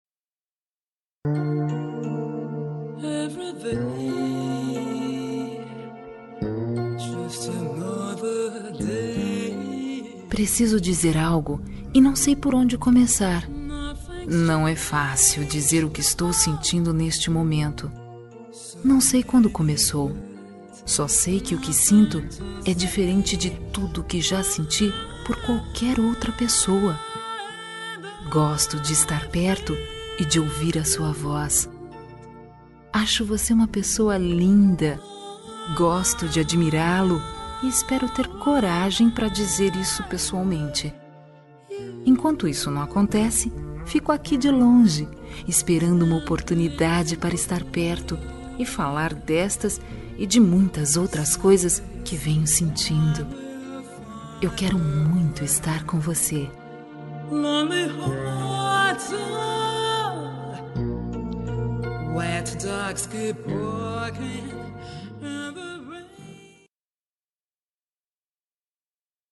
Telemensagem de Paquera – Voz Feminina – Cód:2151
Paquera fem quero estr com você fem 2151.mp3